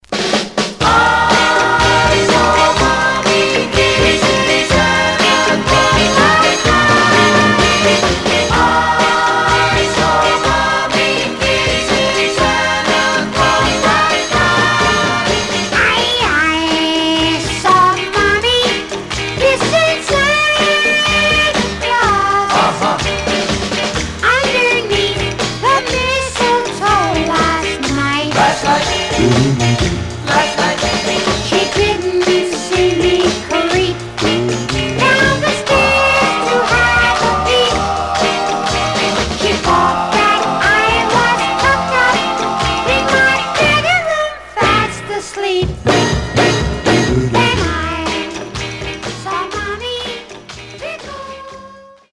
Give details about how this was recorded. Condition Some surface noise/wear Stereo/mono Mono